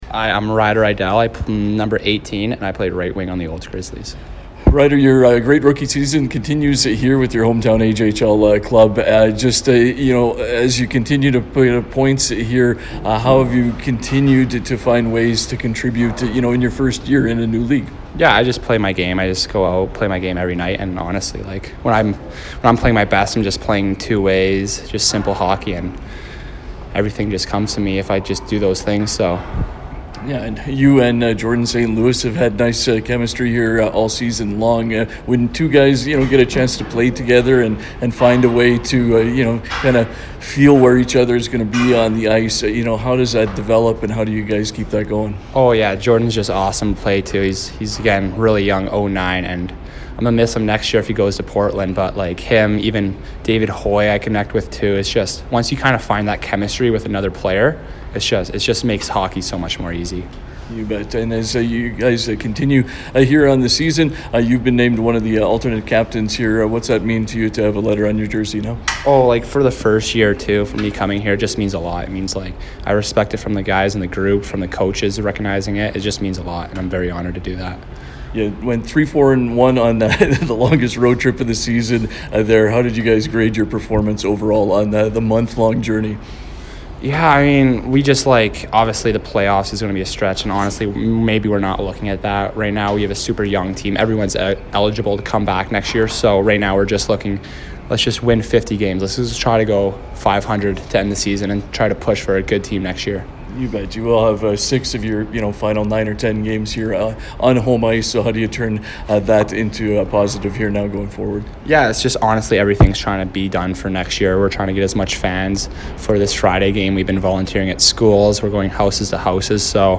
after practice on February 12th